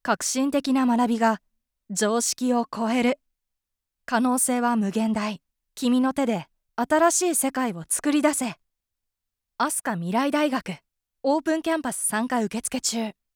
元CATVアナウンサーが温かみのある爽やかな声をお届けします。
誠実な、堂々とした